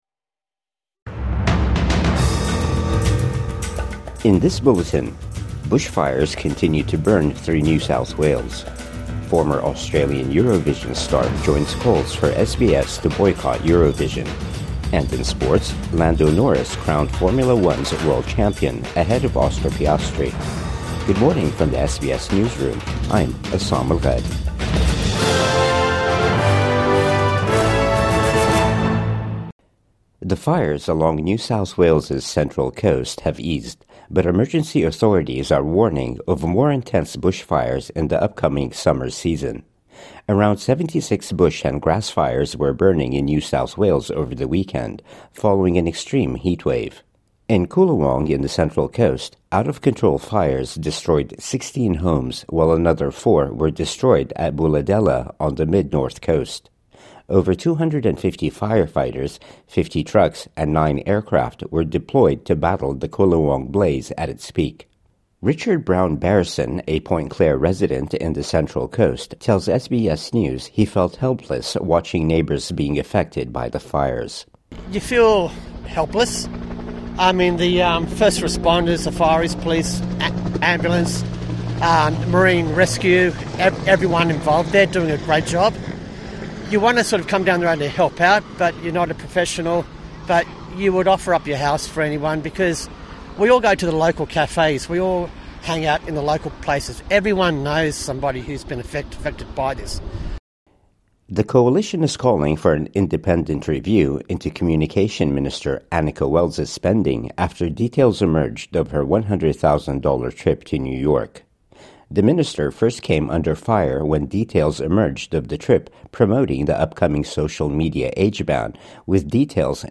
Bushfires continue to burn through New South Wales | Morning News Bulletin 8 December 2025